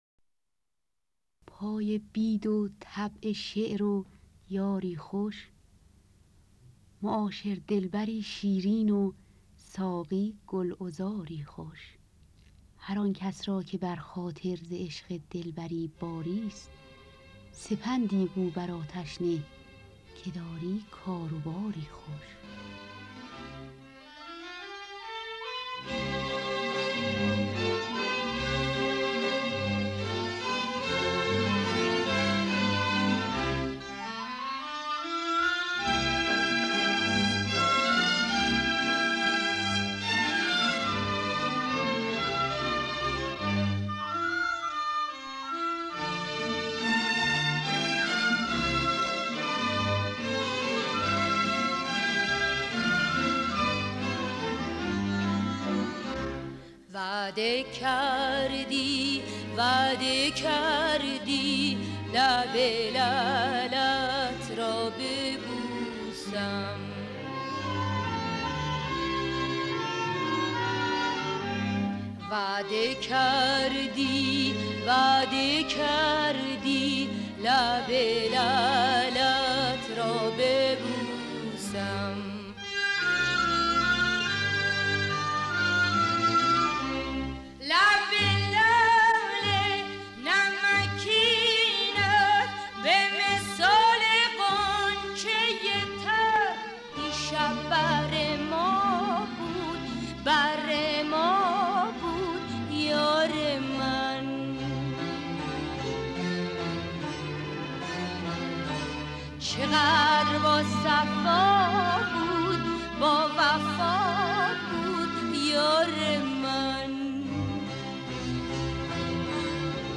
با صدای بانوان